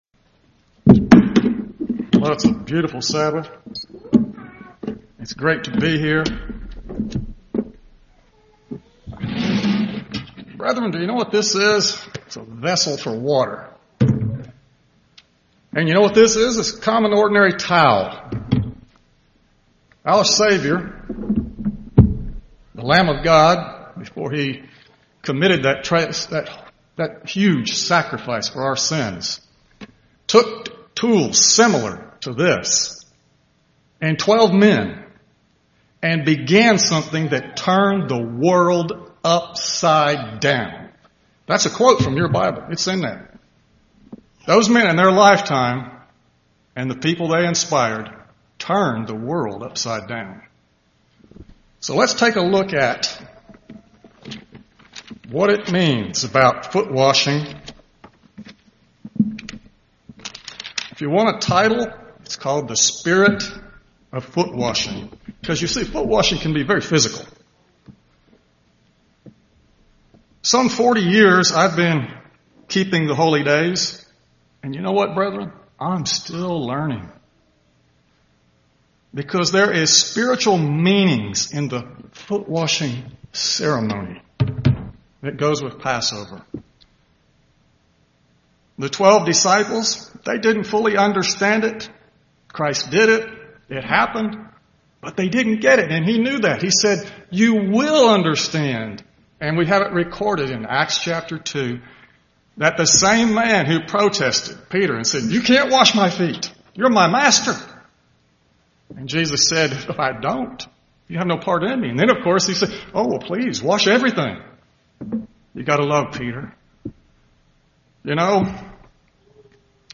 Given in Birmingham, AL
UCG Sermon Studying the bible?